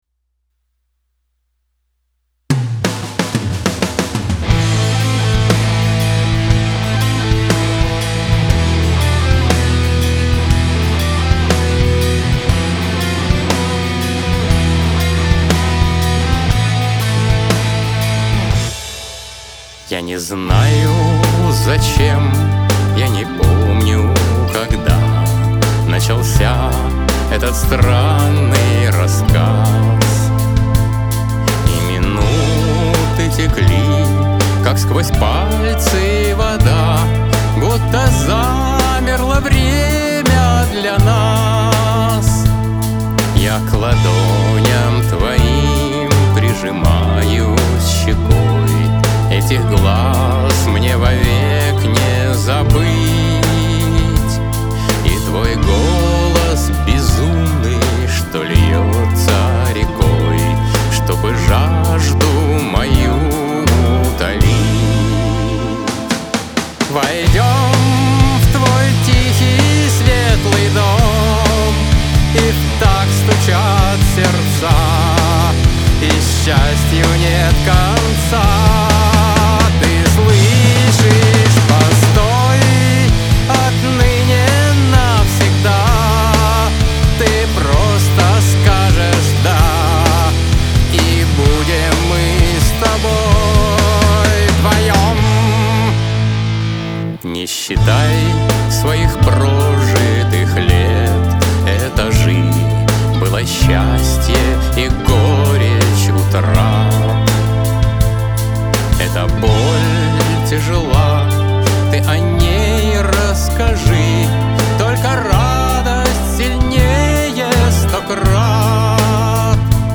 Песня
Вокал